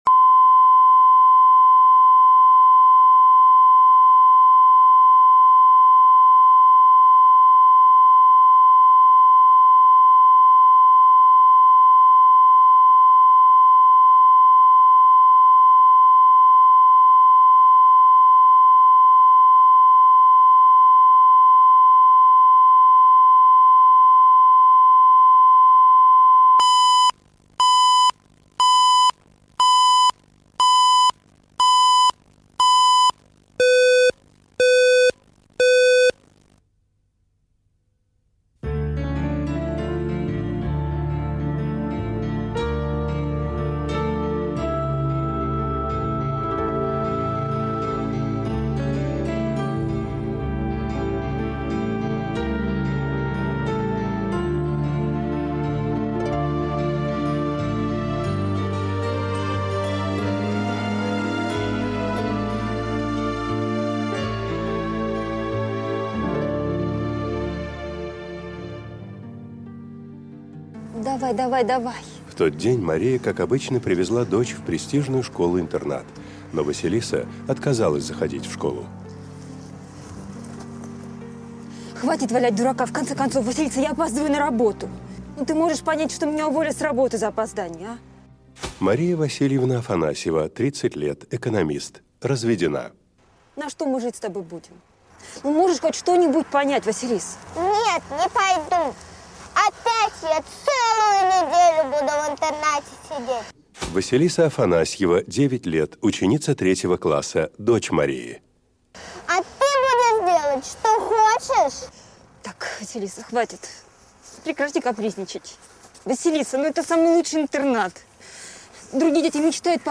Аудиокнига От рождества до рождества | Библиотека аудиокниг
Прослушать и бесплатно скачать фрагмент аудиокниги